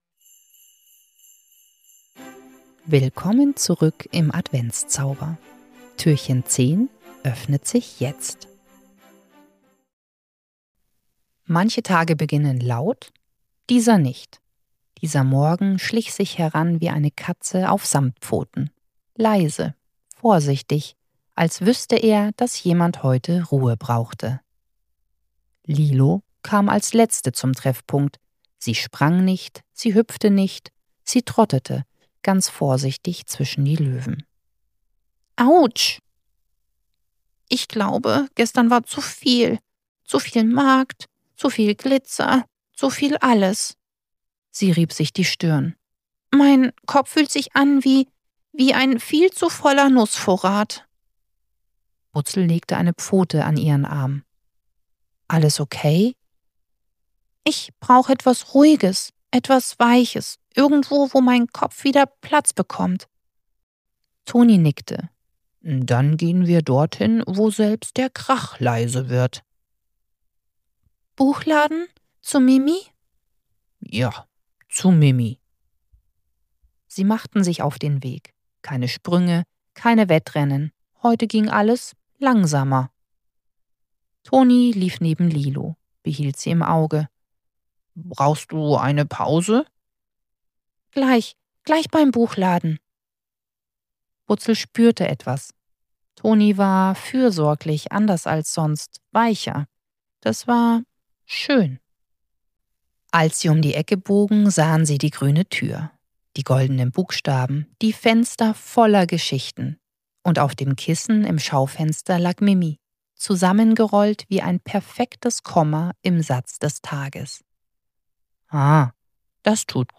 10. Türchen – Butzel und das Geschichten-Werkzeug ~ Butzels Adventskalender – 24 Hörgeschichten voller Herz & kleiner Wunder Podcast